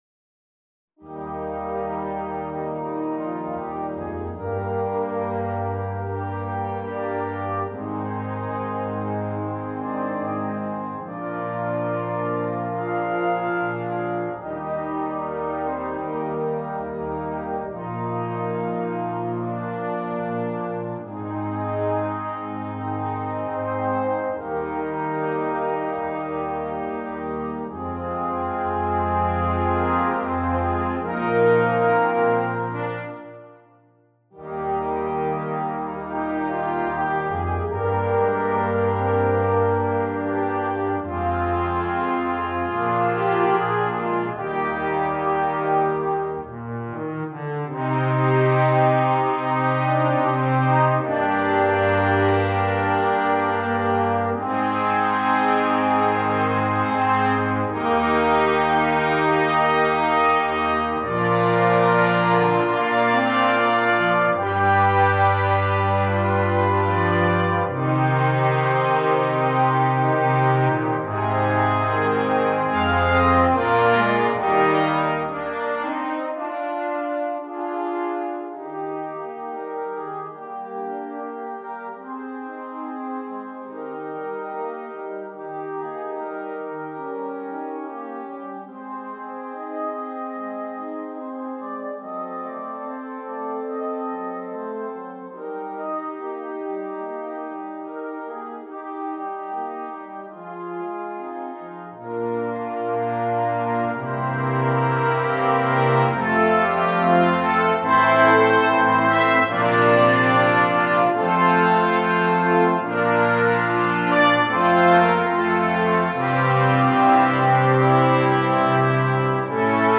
Full Band
without solo instrument
Entertainment